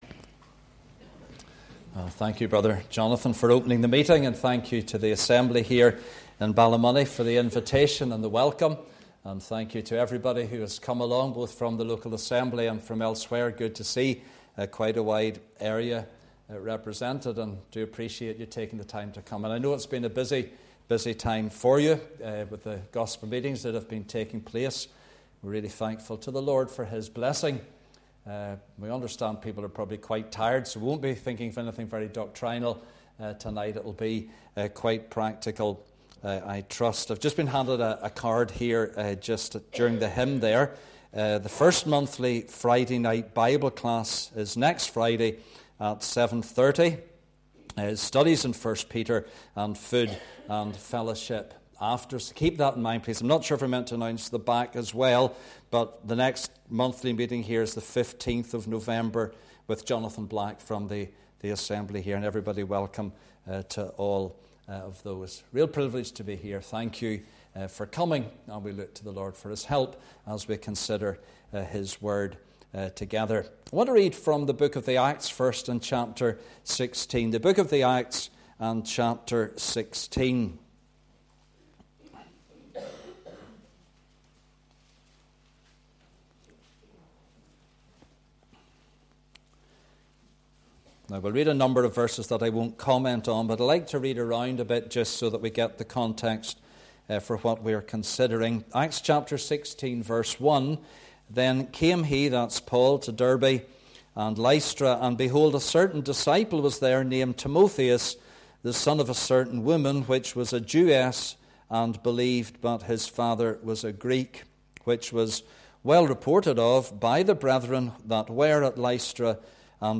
Passage: Acts 16, 2 Cor 5-7, 2 Tim 4, Meeting Type: Ministry